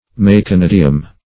Search Result for " meconidium" : The Collaborative International Dictionary of English v.0.48: Meconidium \Mec`o*nid"i*um\, n. [NL., dim. of Gr.
meconidium.mp3